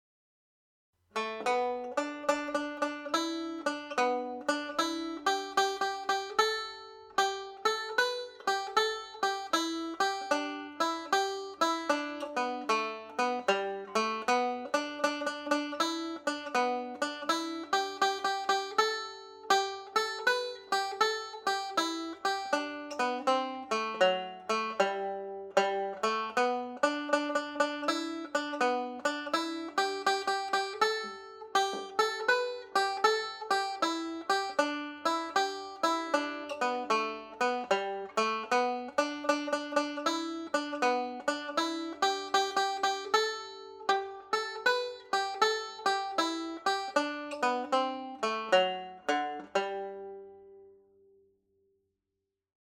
second part played slowly